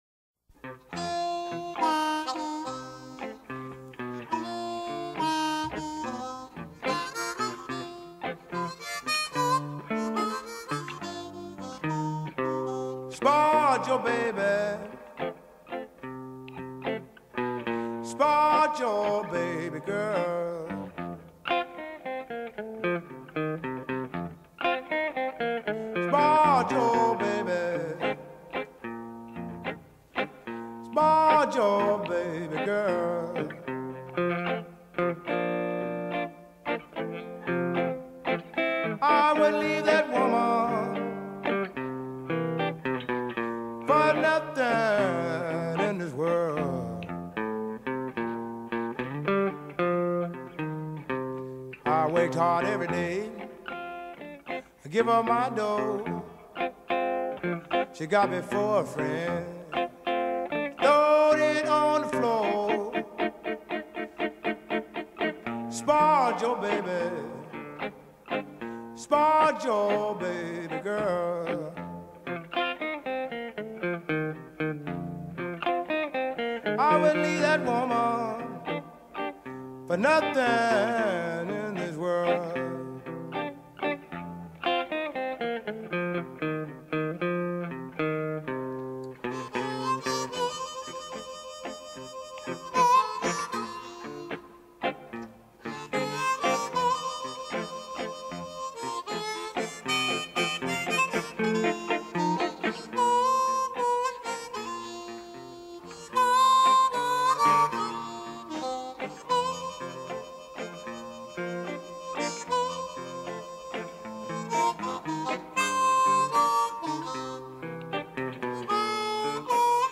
New pressing of this 1960 Blues classic LP.